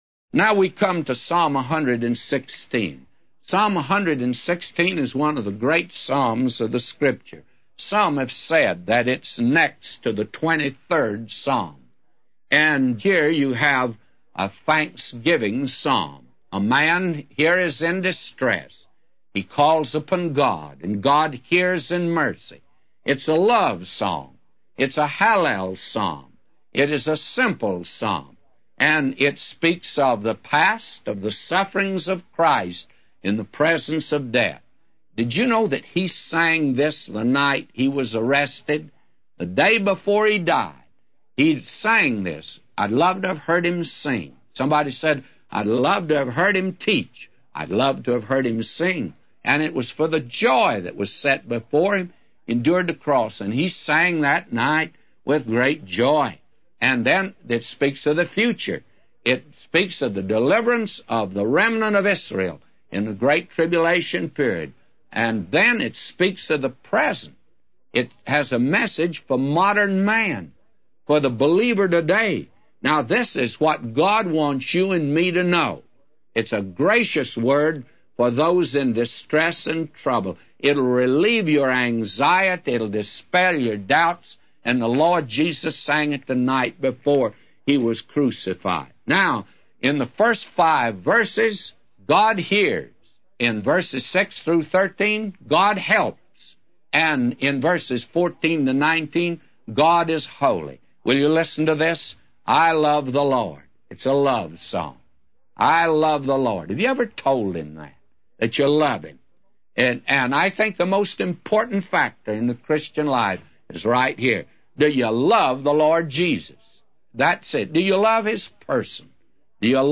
A Commentary By J Vernon MCgee For Psalms 116:1-999